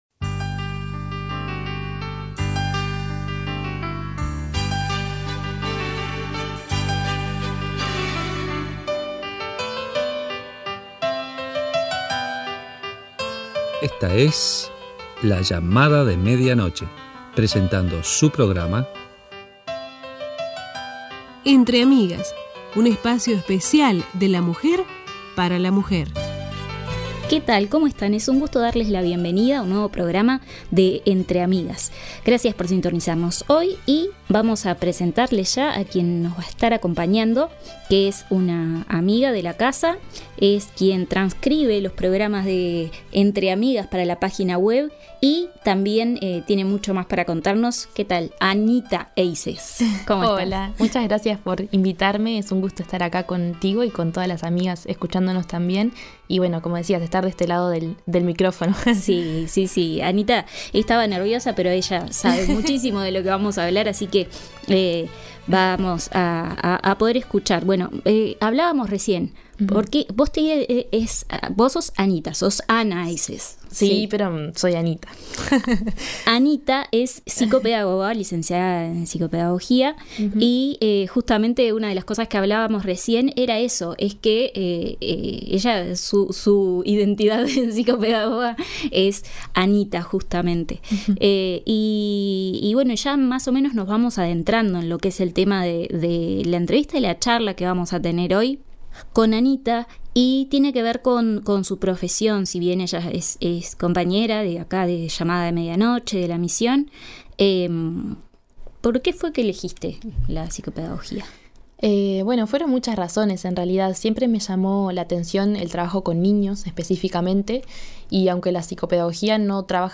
Abordamos temáticas como el Autismo, TDAH, Síndrome de Down, Dislexia, Discalculia y más. No te pierdas de la primera parte de esta entrevista.